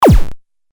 シンセ 8bit 攻撃 ピッチ高
ピュウ